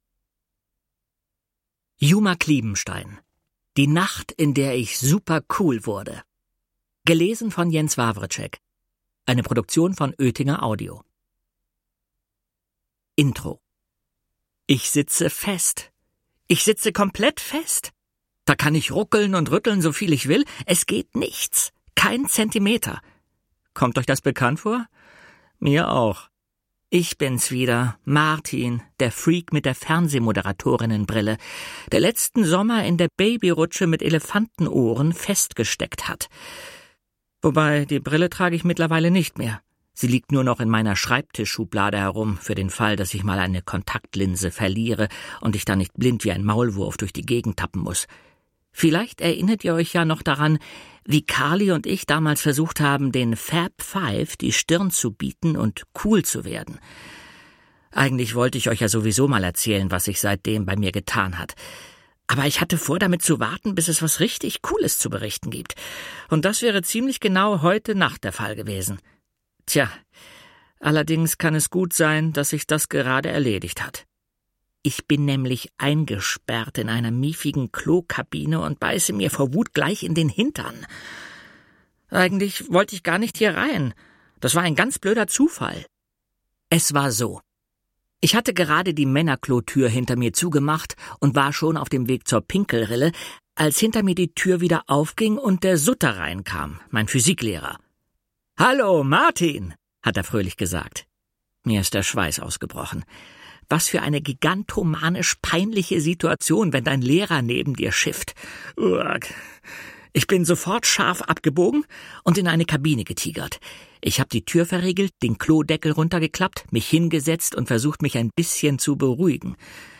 Hörbuch: Der Tag, an dem ich cool wurde 2.